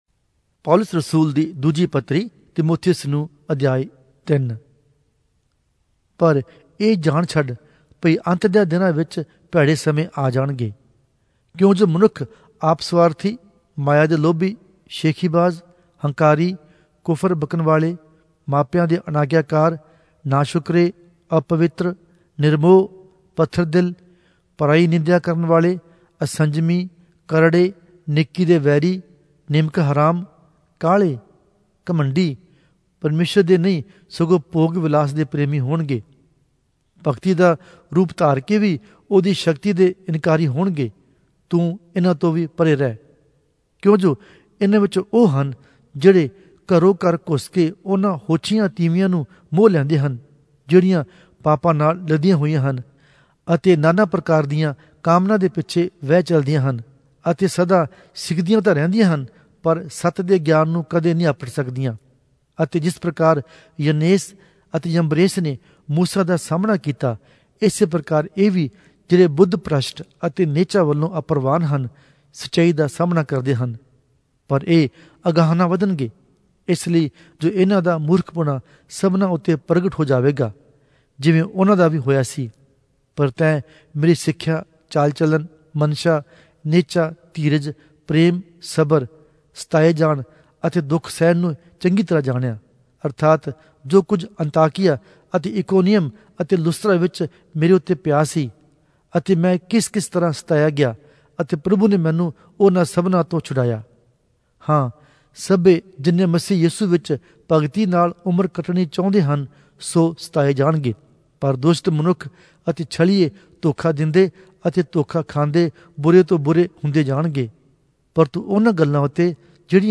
Punjabi Audio Bible - 2-Timothy 3 in Mhb bible version